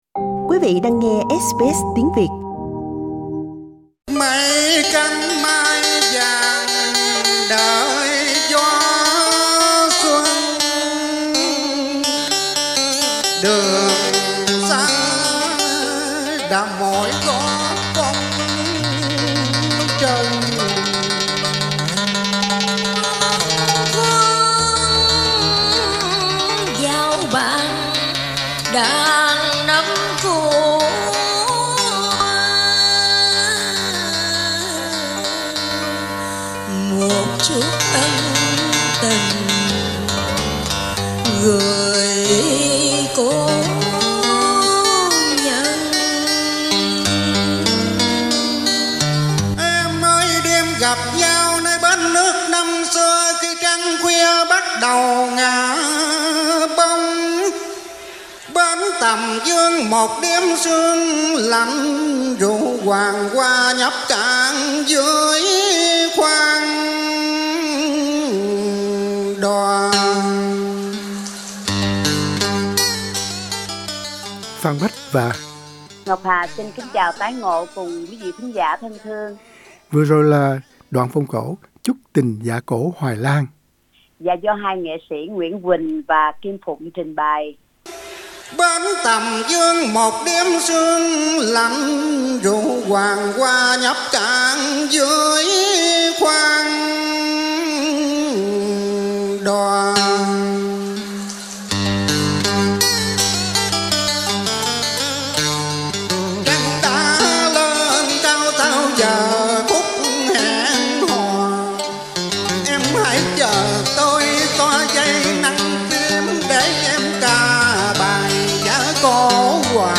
Do tình trạng nới lỏng các hạn chế của đại dịch Covid-19 tại tiểu bang nắng ấm Queesnland, nên nhóm cổ nhạc tại Brisbane có thể qui tụ khoảng 30 thành viên trong nhóm, để tổ chức Giổ Tổ Cải Lương trên thực tế.